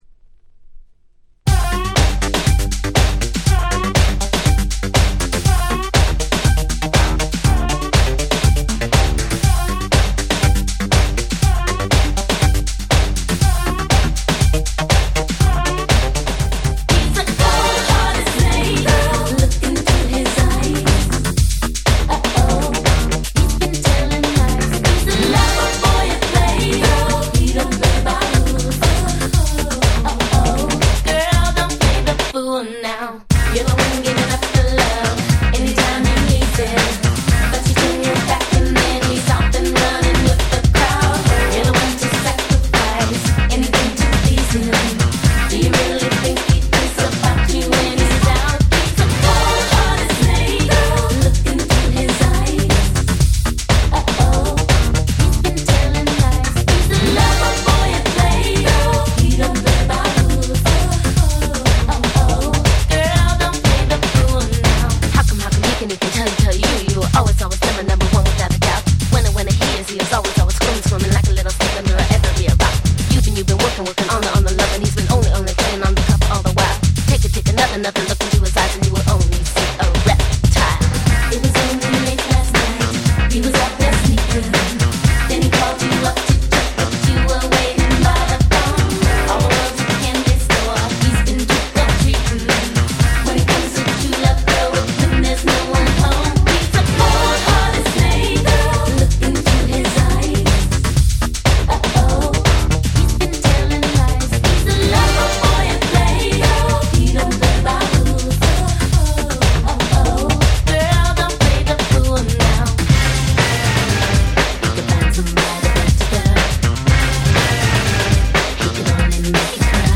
91' Super Hit R&B !!
Popでキャッチーな最高のDanceチューン！！